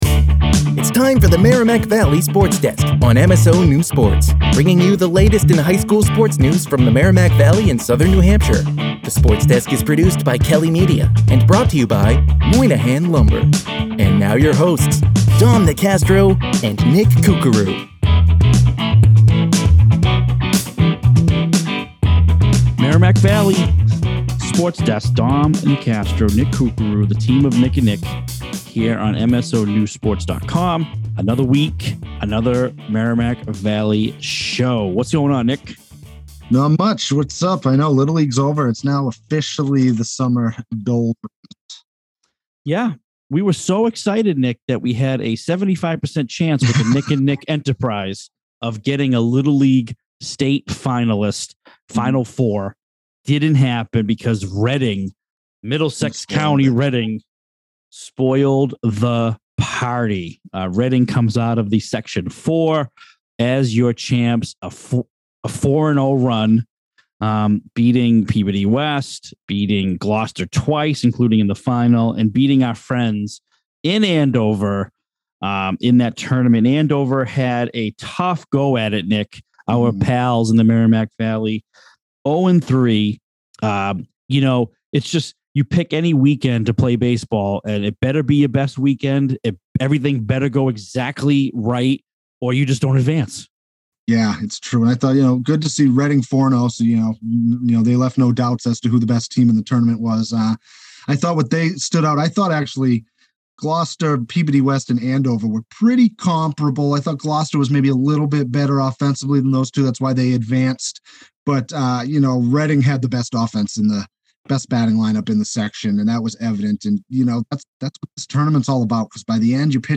Sports talk